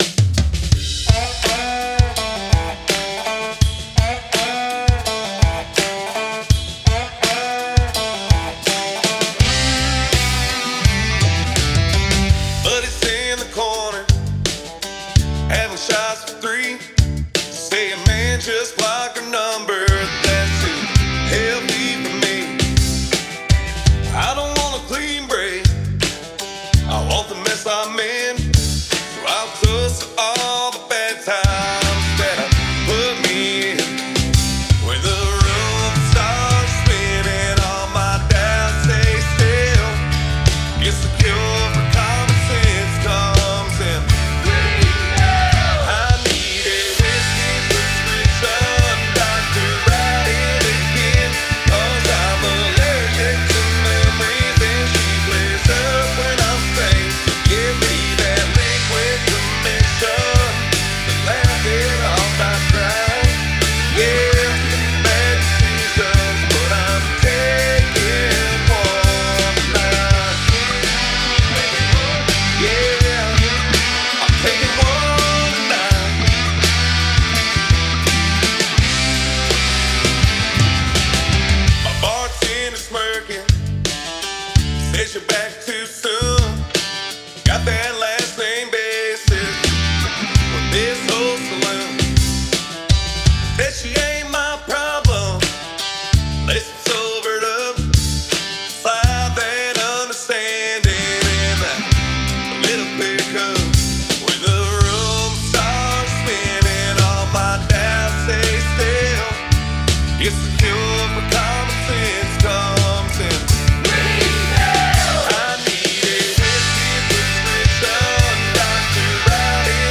country and Southern rock
drums